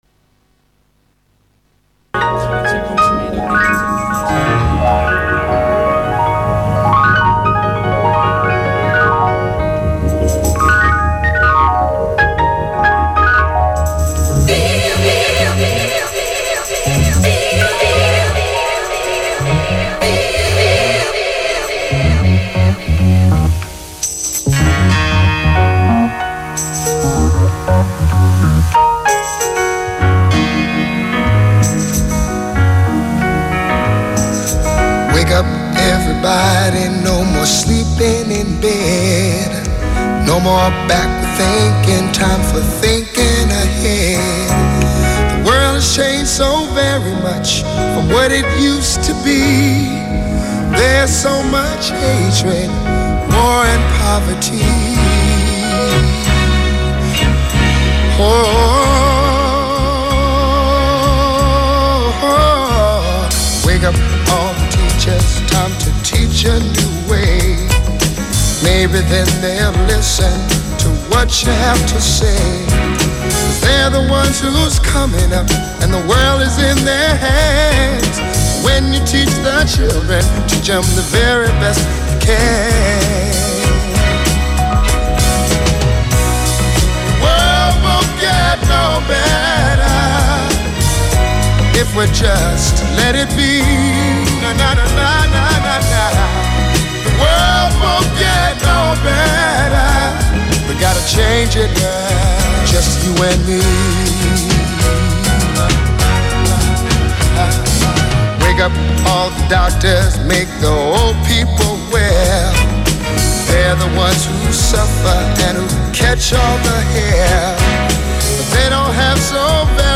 SOUL & JAZZ